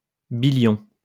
wymowa:
IPA/bi.ljɔ̃/